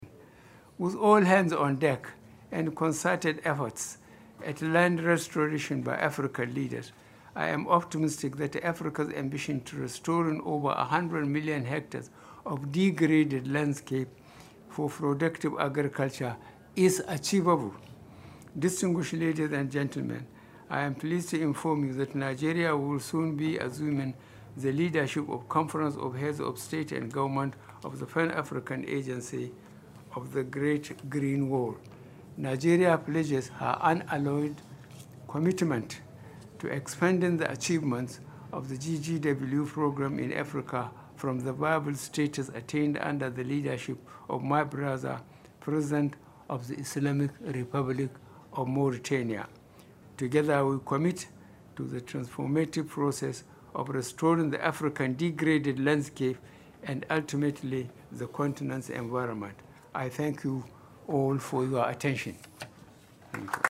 Buhari expressed this optimism at the Climate Change Summit, COP 26, side event on the Great Green Wall (GGW), co-hosted by French President Emmanuel Macron, the Prince of Wales, and the Mauritanian President Mohamed Ould Ghazouani.
PRESIDENT-BUHARI-ON-GREAT-GREEN-WALL.mp3